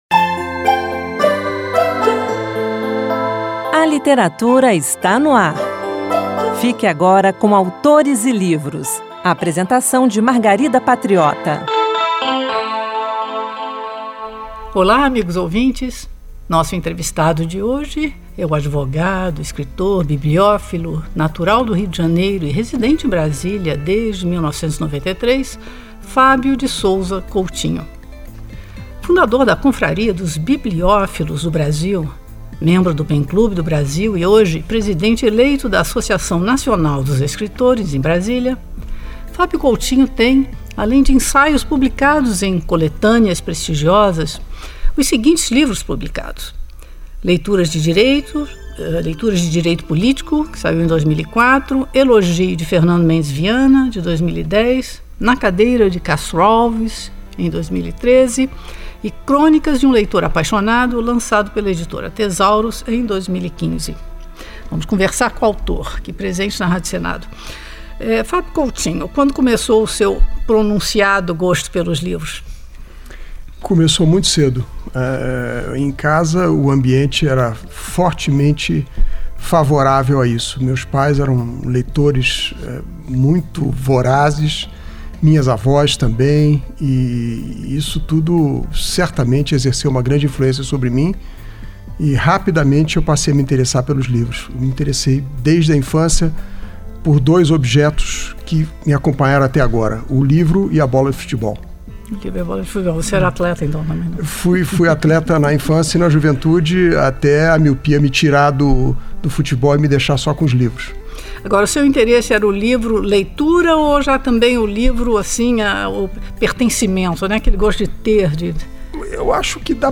Revista literária com entrevistas com autores, poesias, dicas de livros e também notícias sobre o mundo da literatura e as últimas publicações do Senado Federal